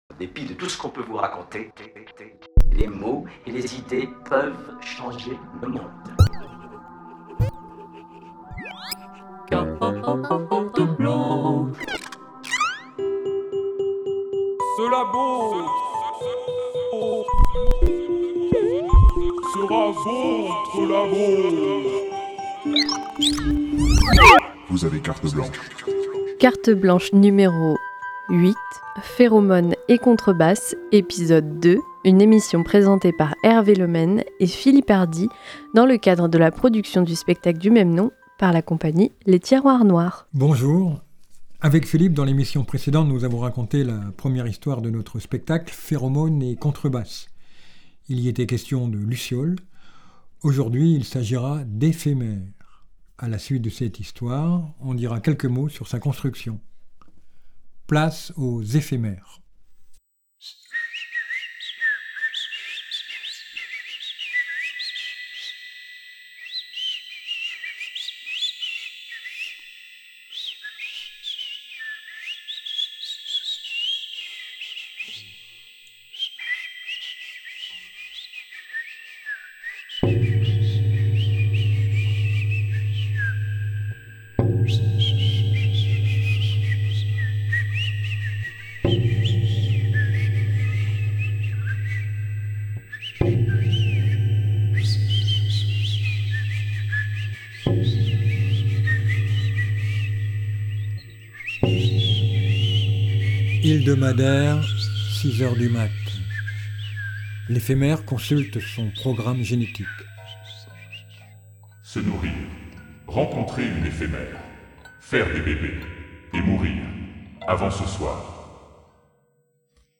La compagnie de théâtre Les Tiroirs Noirs propose une version radiophonique de son dernier spectacle, suivie d’une discussion sur sa construction. Dans ce deuxième épisode, un éphémère consulte son programme génétique et refuse de se limiter à sa fonction biologique.